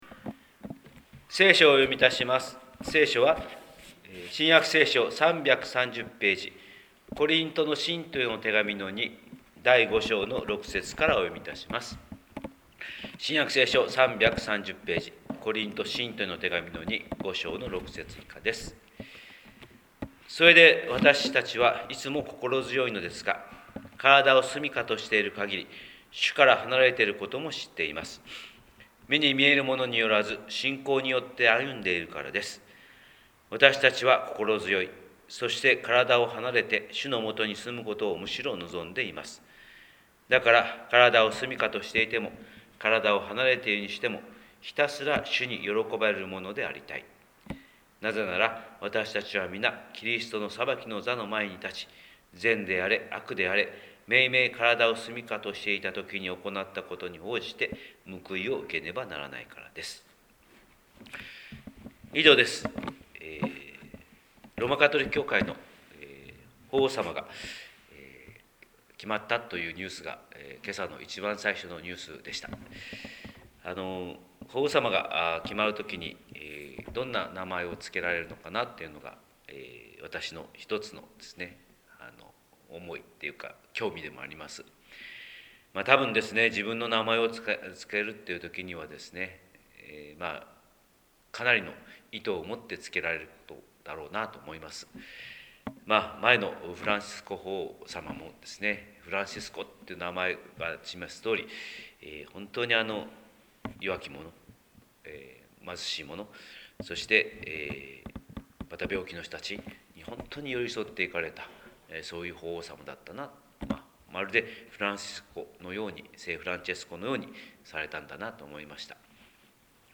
広島教会朝礼拝250509「信仰によってありたい」